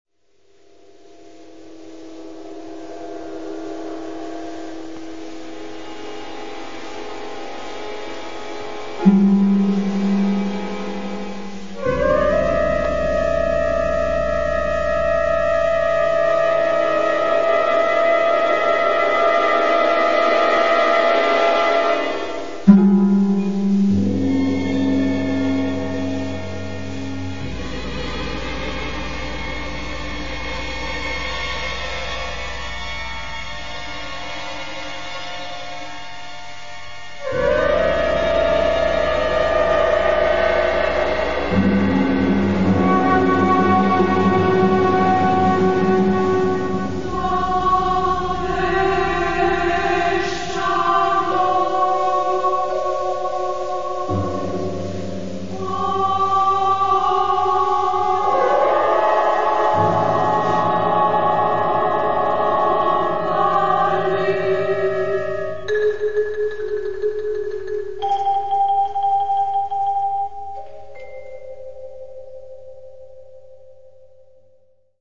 SSMMAA (6 voix égales de femmes) ; Partition complète.
Aléatoire.
Caractère de la pièce : poétique ; dramatique
Solistes : Soprano (1) (1 soliste(s))
Tonalité : clusters ; atonal